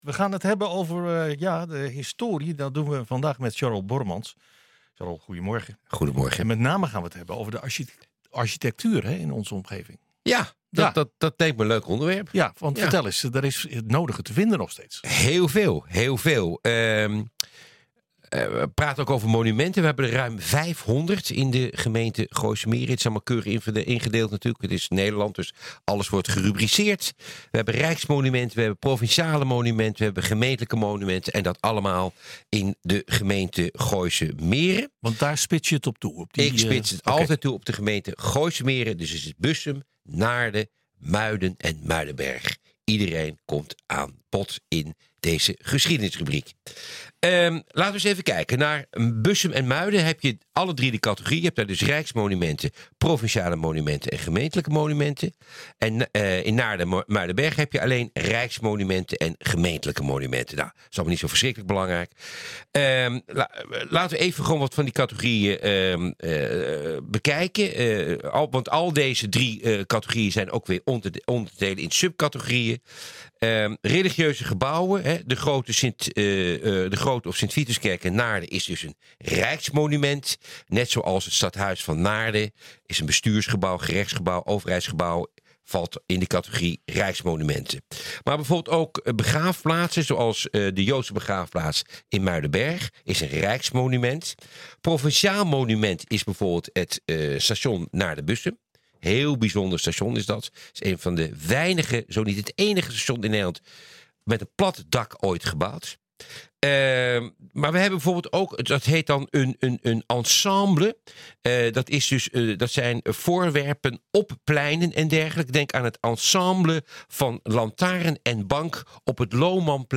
Voor dit interview gaan we deze week in gesprek over de rijke architectuur-geschiedenis van Gooise Meren. Er zijn honderden monumenten te vinden in deze gemeente, variërend van scholen, kerken en villa’s, en allerlei andere bijzondere objecten. We zoomen in op drie architecten die een duidelijke stempel hebben gedrukt op Bussum en omgeving: Gerrit Jan Vos, Cornelis Kruisweg en Johan Frederik Everts.